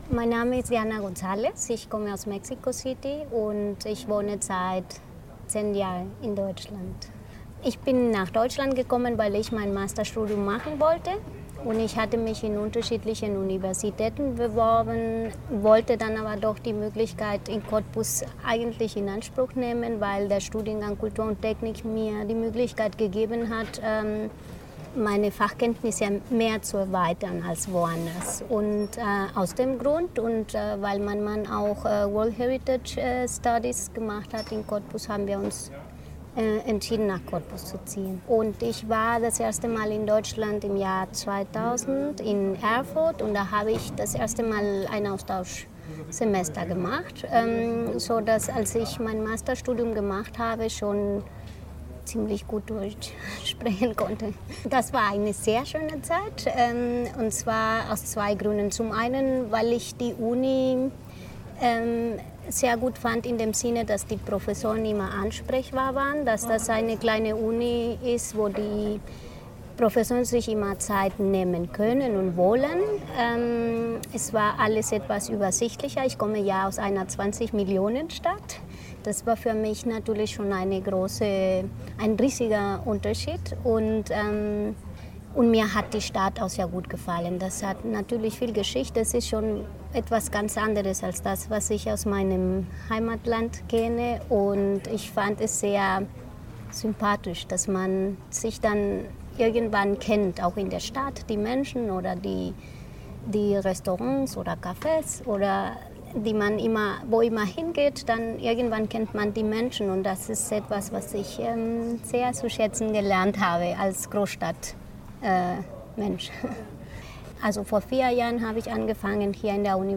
Mehr im Audio-Interview (4:00 Min.)